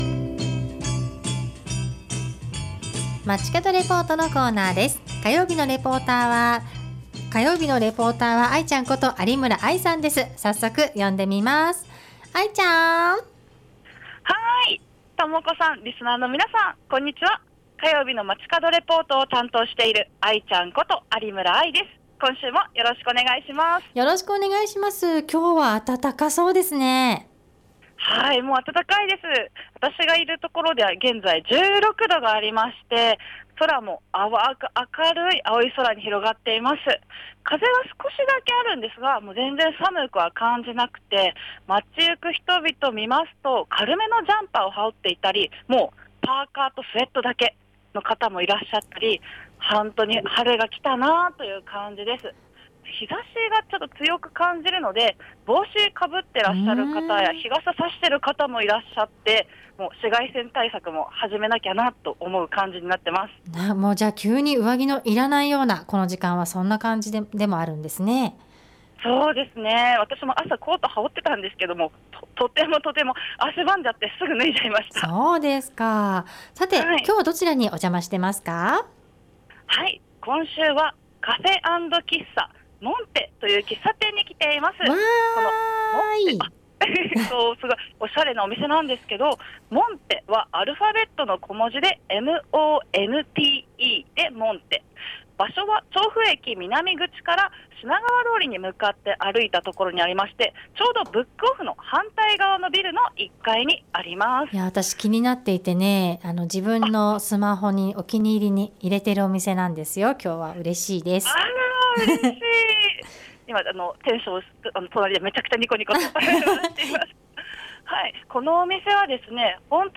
今週は調布駅南口から徒歩5分にある昭和レトロな喫茶店「カフェ&喫茶monte」からお届けしました！